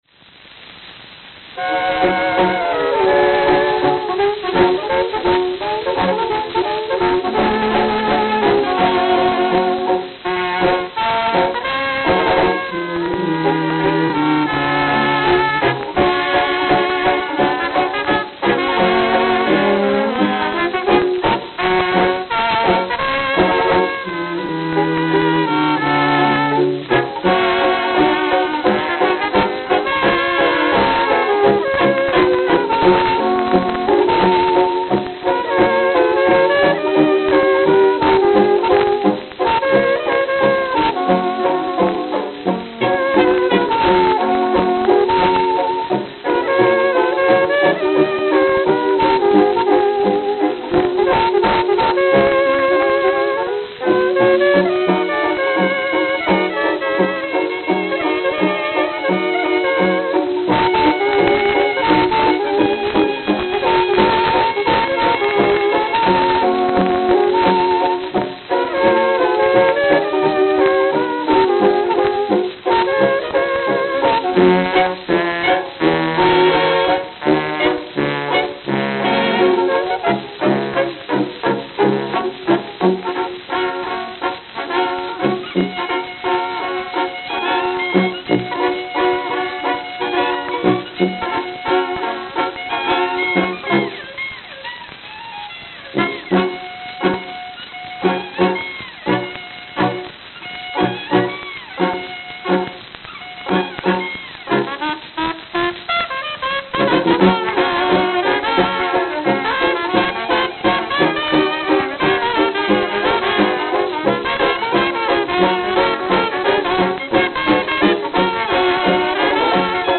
Note: Very worn.